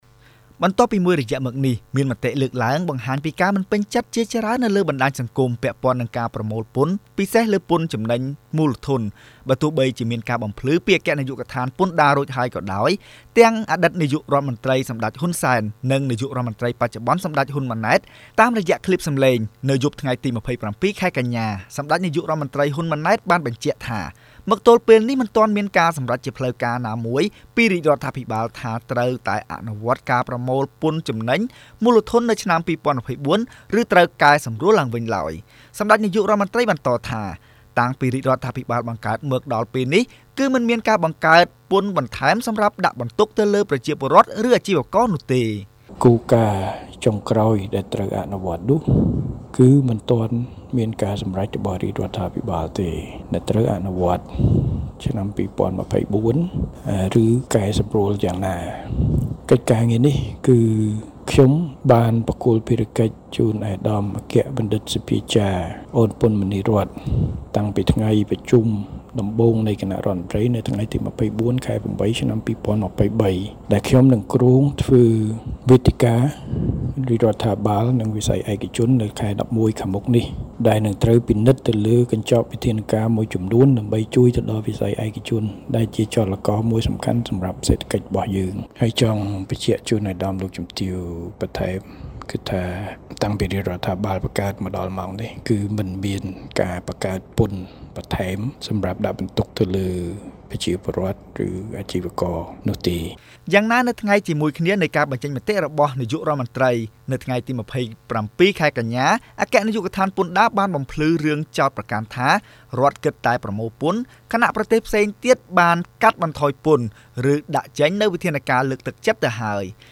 Radio
រាយការណ៍